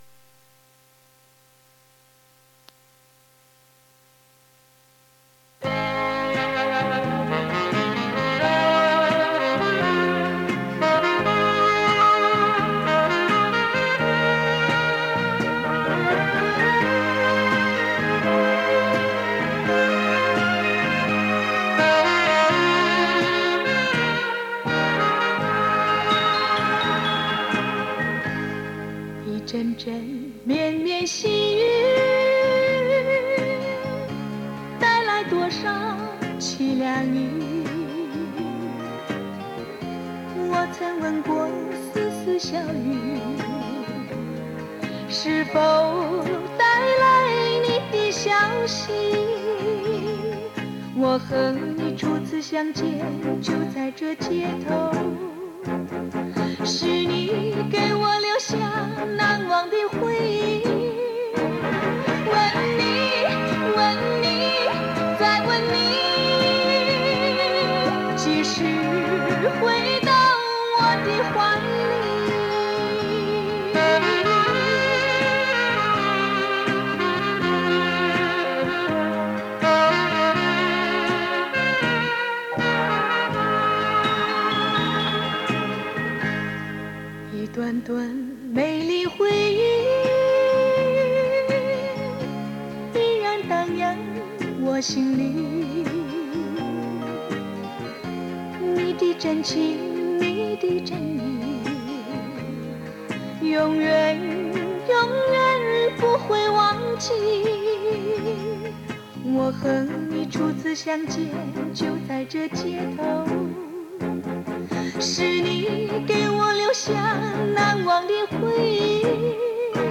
磁带数字化：2022-05-29